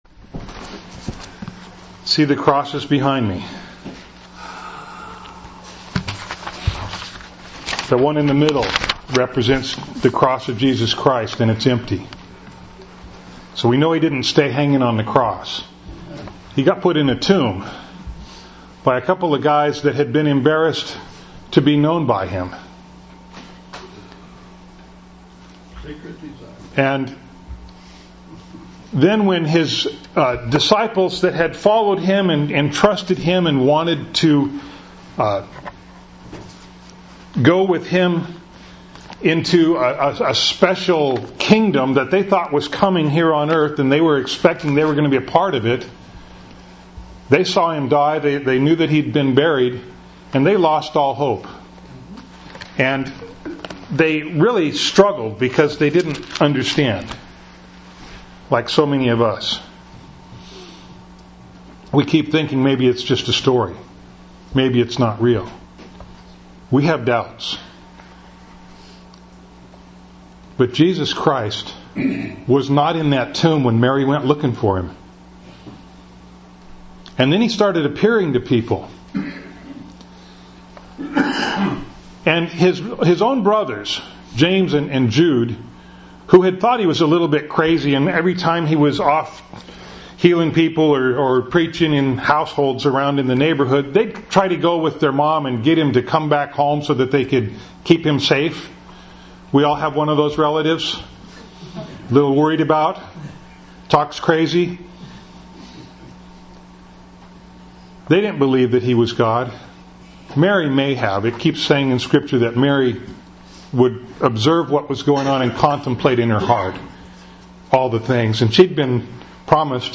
Bible Text: Genesis 6:5 | Preacher